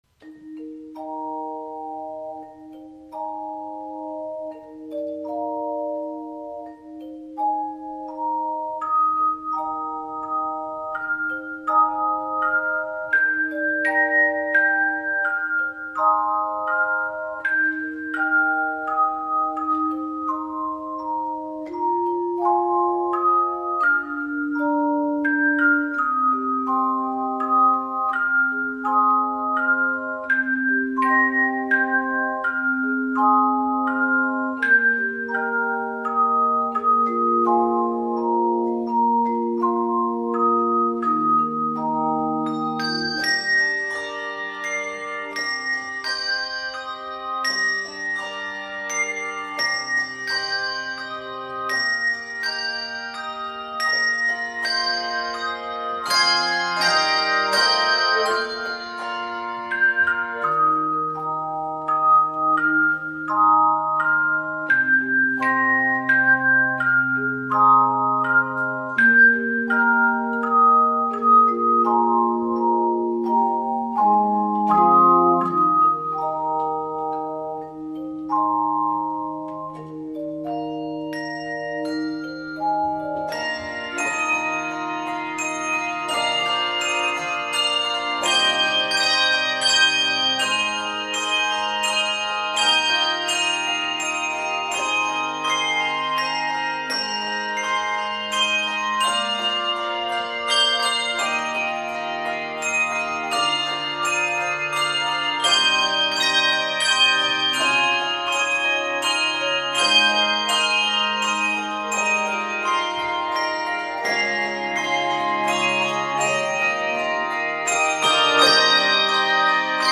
This Scottish tune